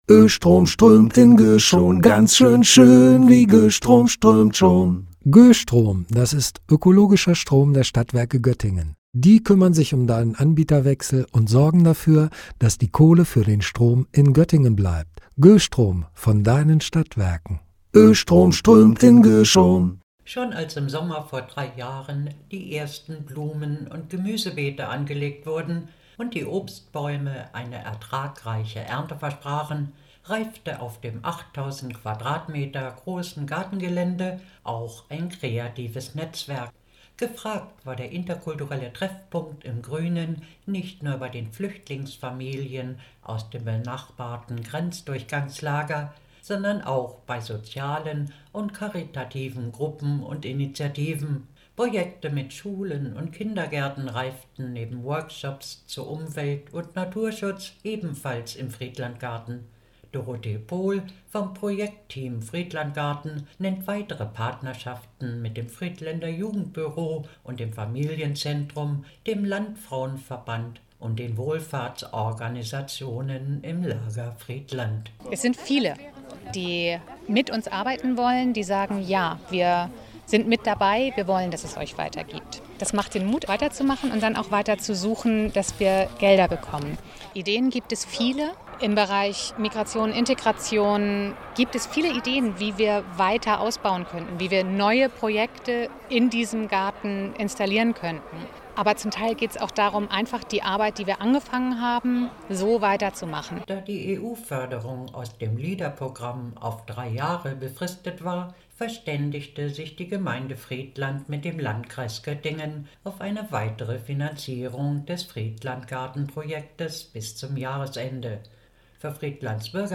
Bei einem Treffen im FriedlandGarten zog das Projektteam Bilanz über eine Vielzahl von Bildungsangeboten und Aktionen auf dem Gartengelände. Zur Diskussion standen dabei auch die Frage der langfristigen Förderung für den Lern- und Naturerlebnisraum.